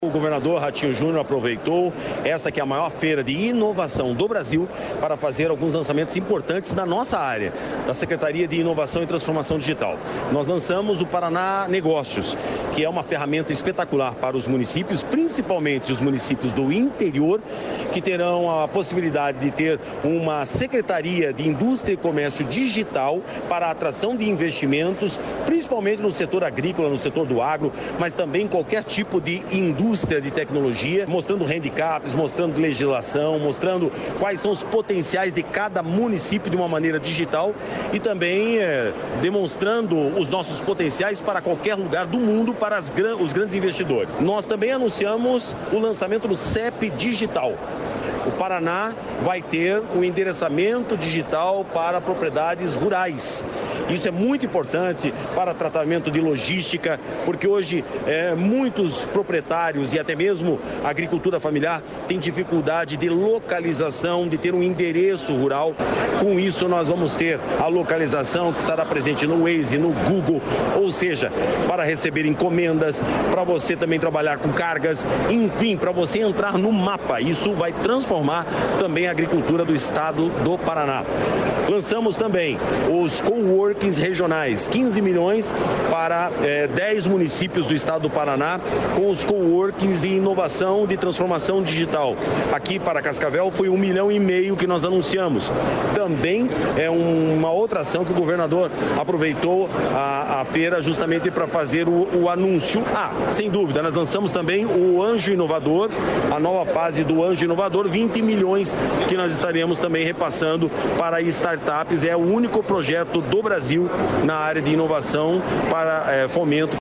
Sonora do secretário de Inovação, Modernização e Transformação Digital, Marcelo Rangel, sobre o pacote de inovações anunciado